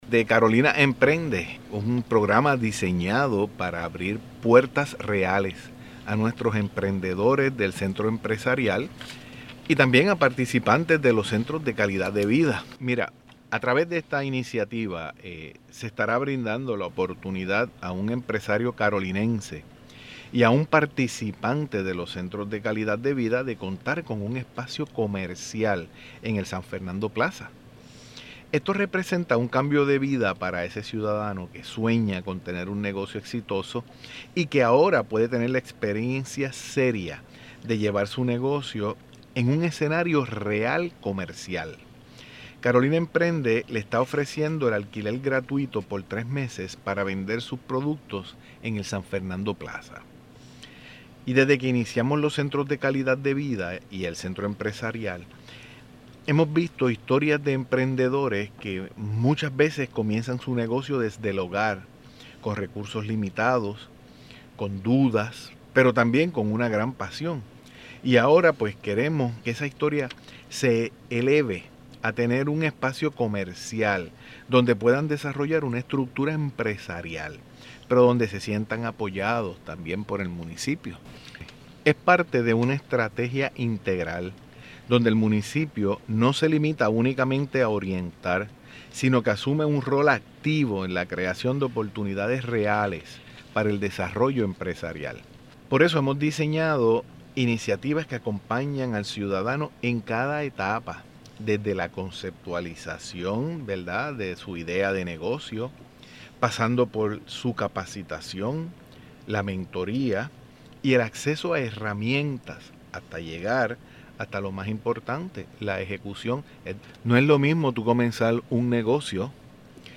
“Centros de Calidad de Vida” son espacios diseñados para el desarrollo integral de los ciudadanos”, dice el alcalde de Carolina (sonido)